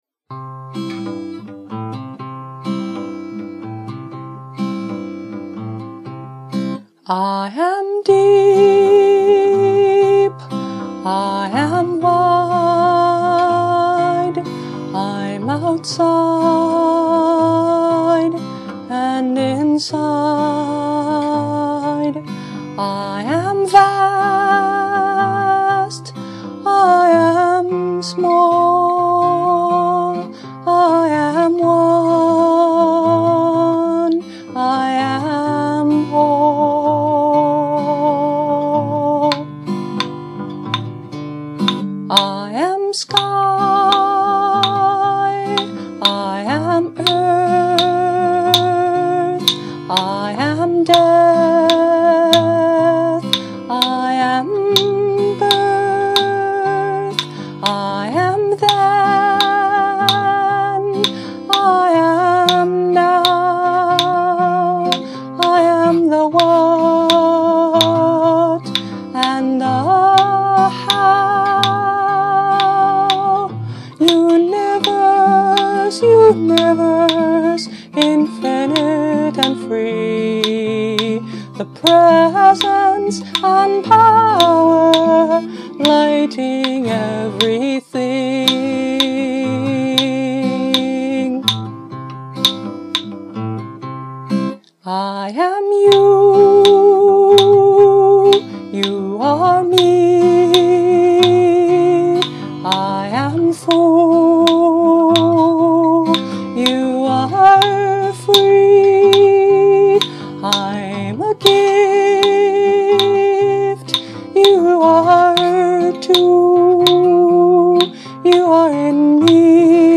Claves